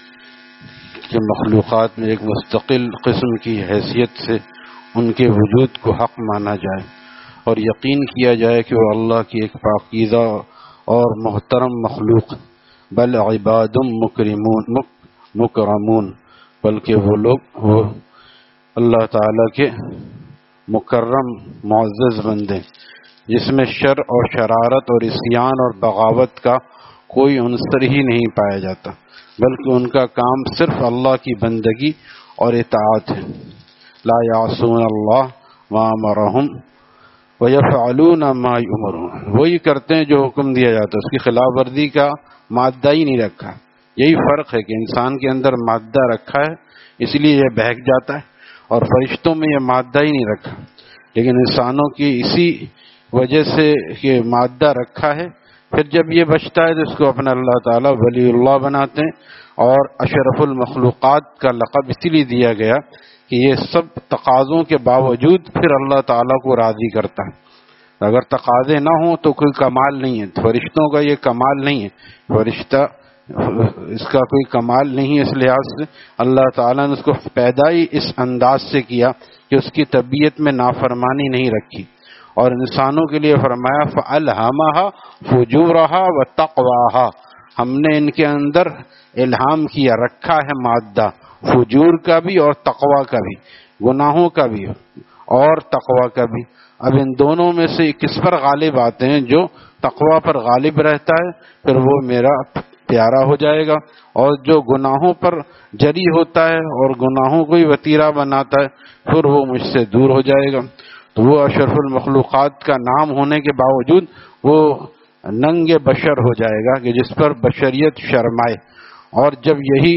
Taleem After Fajor at Jama Masjid Gulzar e Muhammadi, Khanqah Gulzar e Akhter, Sec 4D, Surjani Town